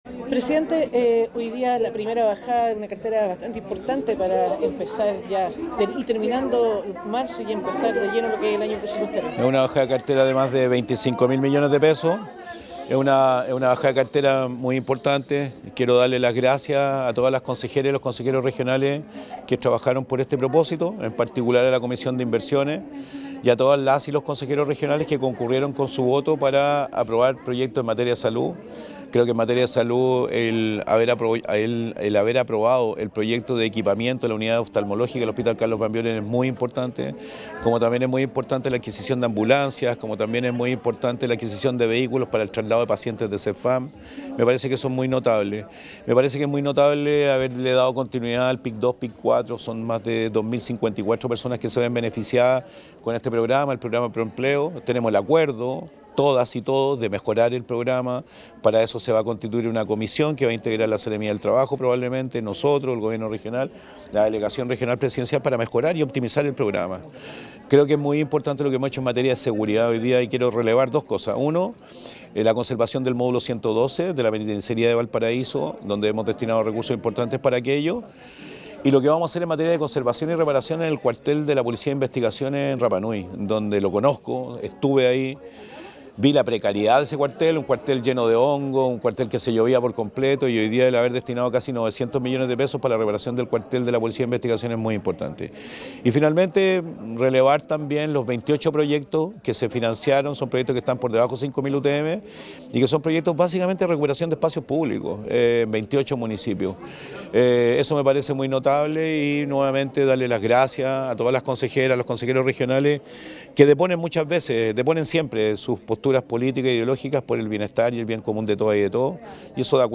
El Gobernador Regional de Valparaíso, Rodrigo Mundaca, valoró esta aprobación.